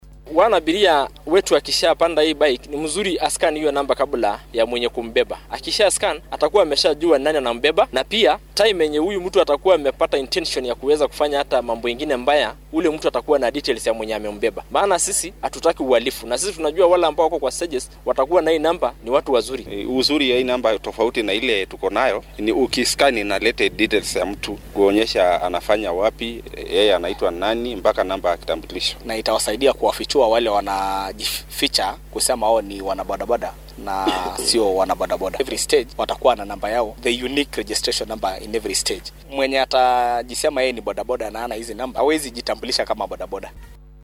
Ururka mootooleyda ee ismaamulka Kajiado ayaa soo saaray qaab casri ah oo rakaabka u fududeynaya in ay aqoonsadaan wadayaasha dhugdhugleyda. Barnaamijkan ayaa la doonaya in looga hor tago falal dambiyeedyada la gaysta iyadoo la adeegsanaya mootada. Qaar ka mid ah wadayaasha mootooyinka ee Kajiado oo arrintan ka hadlay ayaa yiri.